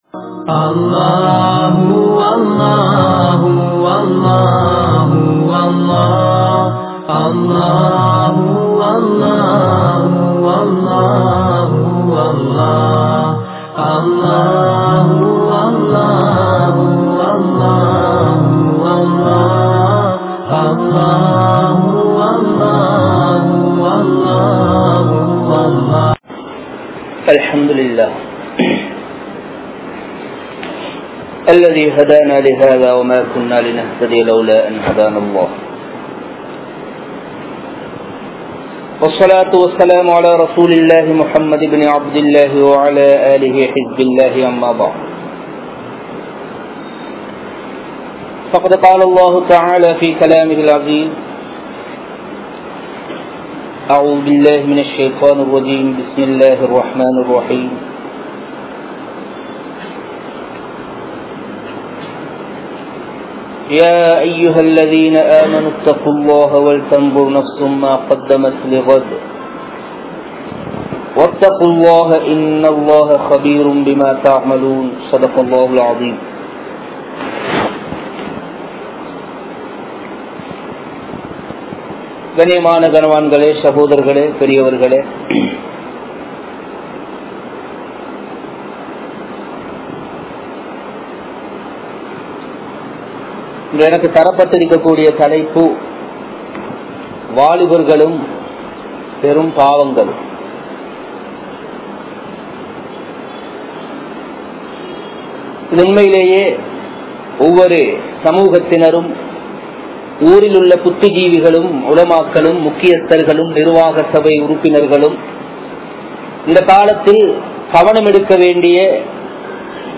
Indraya Vaalifarhalum Perum Paavangalum (இன்றைய வாலிபர்களும் பெரும் பாவங்களும்) | Audio Bayans | All Ceylon Muslim Youth Community | Addalaichenai
Muhiyaddeen Grand Jumua Masjith